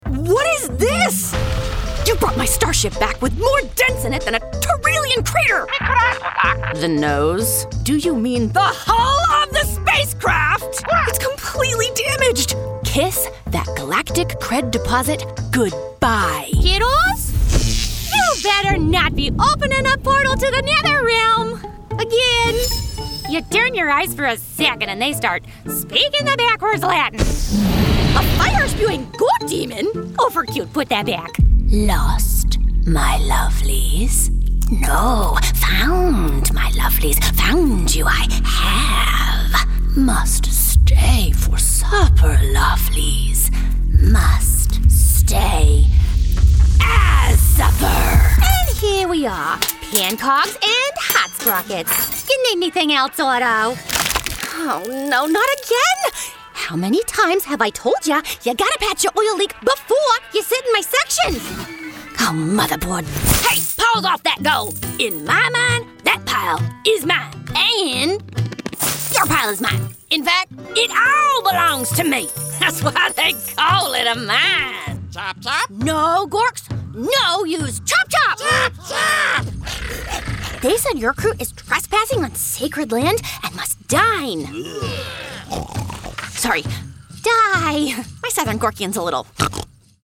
ANIMATION VOICEOVER DEMOS
A world-class, commercial grade studio in North Hollywood, CA is where all my recording takes place.